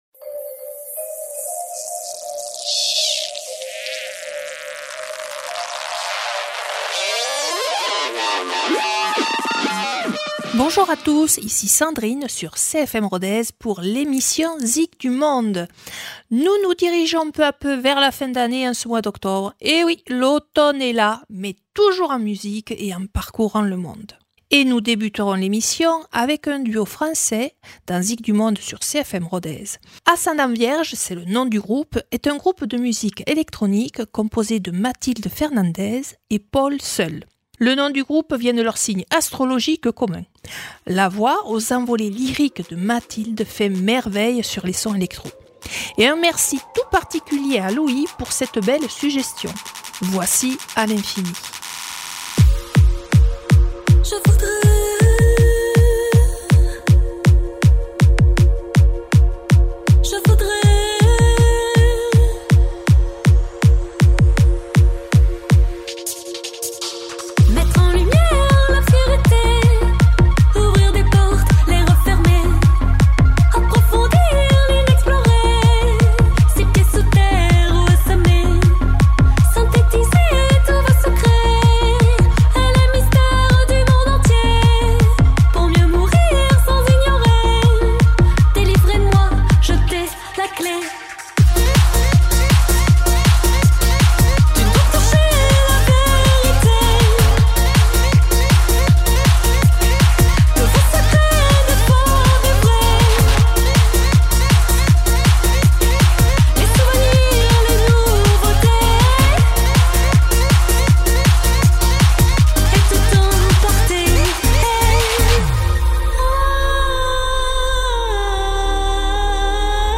Les feuilles mortes se ramassent à la pelle chanterait Montand. C’est donc en musique que je vous invite pour un voyage automnal sur les 5 continents.